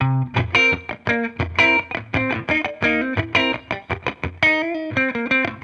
Index of /musicradar/sampled-funk-soul-samples/85bpm/Guitar
SSF_TeleGuitarProc2_85C.wav